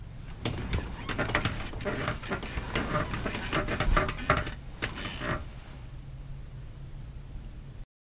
床上的吱吱声
描述：这是一个人从床上下来时发出的吱吱声，用佳能数码摄像机GL2用DAK超指向性枪型麦克风UEM83R
Tag: 卧室 弹簧床垫 床垫